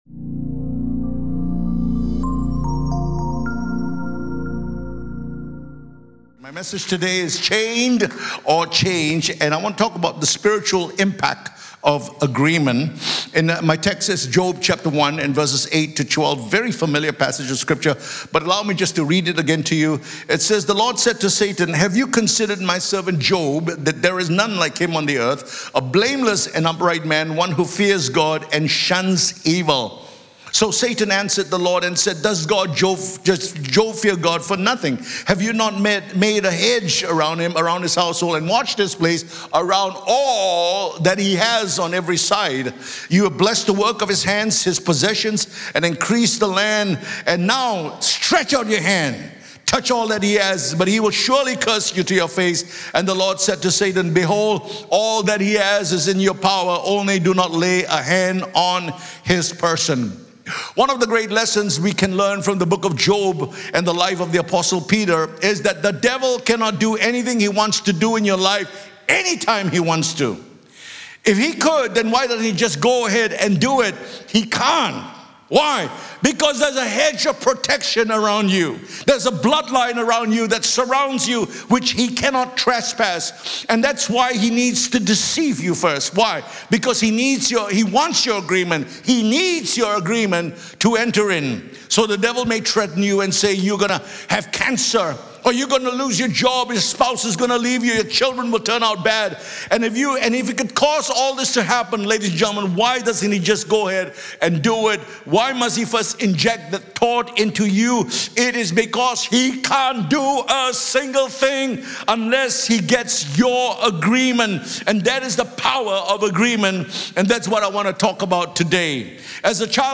In this week’s message on ‘Chains or Change’, we explore the spiritual impact and importance of agreement.